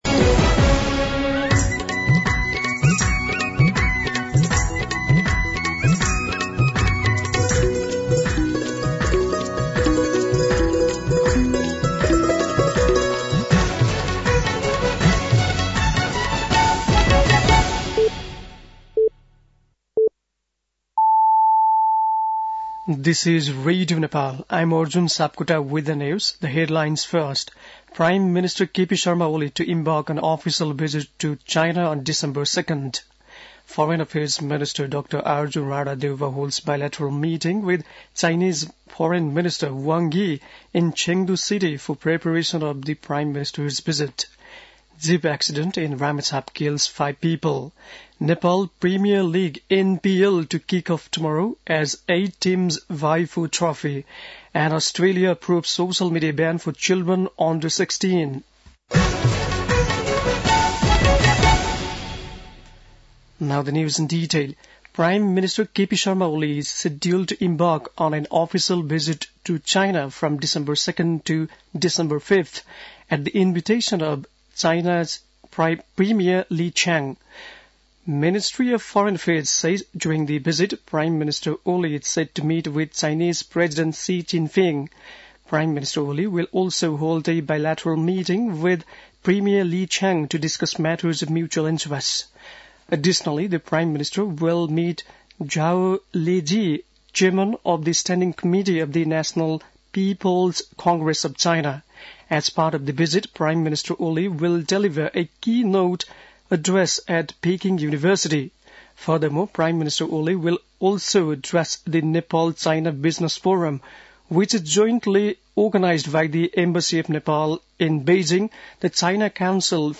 बेलुकी ८ बजेको अङ्ग्रेजी समाचार : १५ मंसिर , २०८१
8-PM-English-News-8-14.mp3